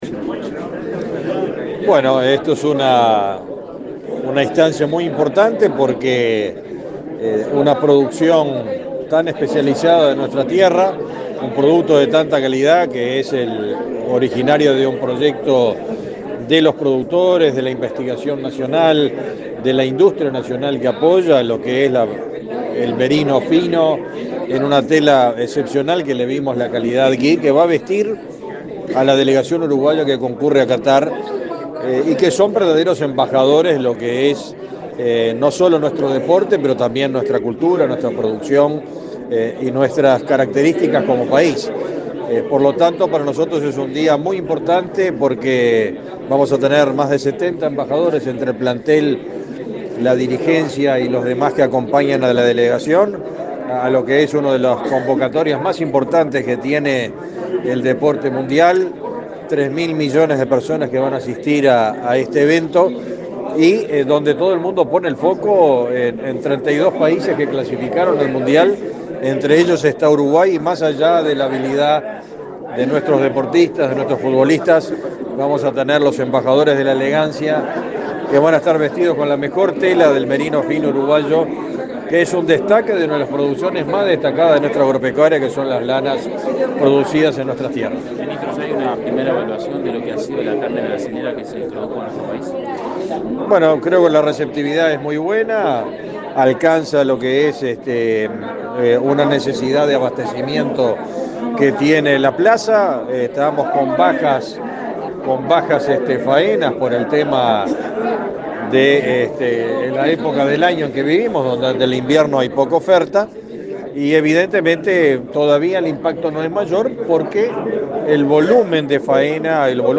Declaraciones a la prensa del ministro de Ganadería, Fernando Mattos